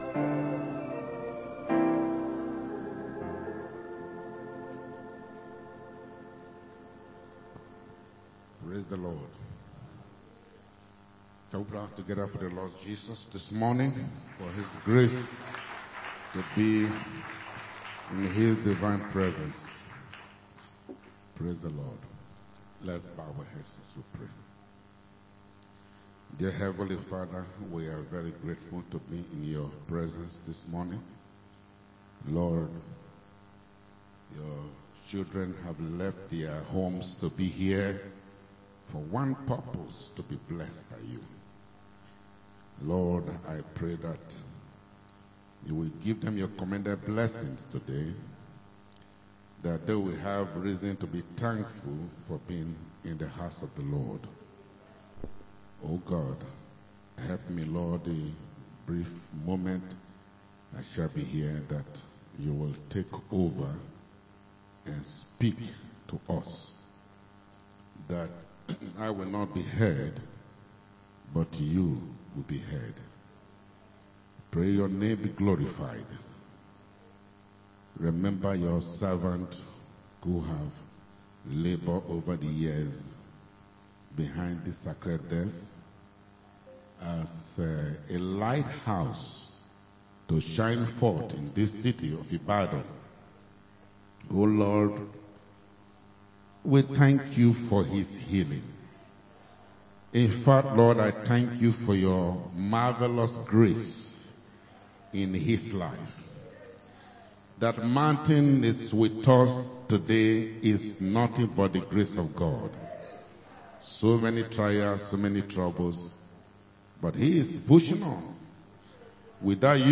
Adult Sunday School 21-09s-25